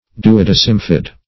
Search Result for " duodecimfid" : The Collaborative International Dictionary of English v.0.48: Duodecimfid \Du`o*dec"im*fid\, a. [L. duodecim twelve + findere to cleave.] Divided into twelve parts.